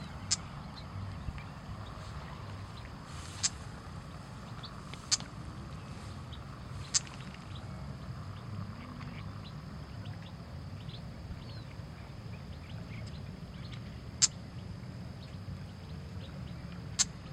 Wren-like Rushbird (Phleocryptes melanops)
Detailed location: Dique Río Hondo
Condition: Wild
Certainty: Photographed, Recorded vocal